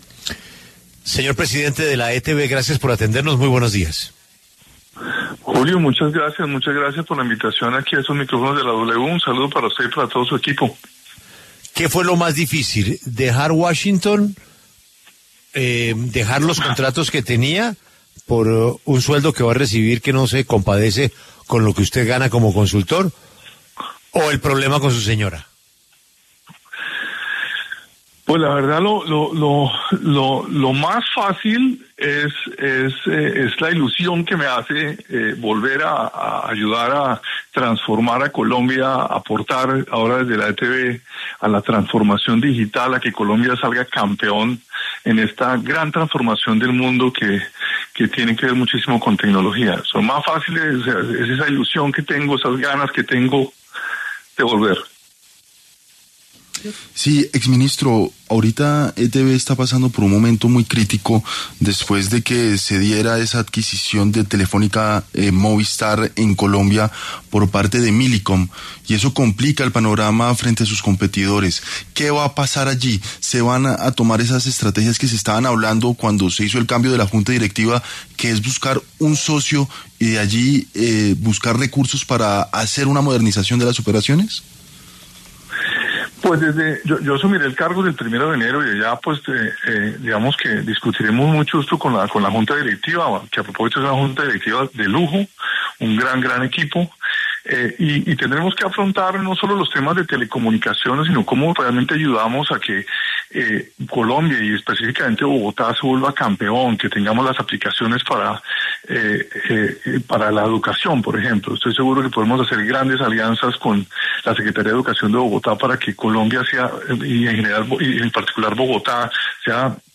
Diego Molano, nuevo presidente de la ETB, conversó con La W y planteó alternativas estratégicas para el futuro de la compañía.